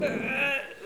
agonie_03.wav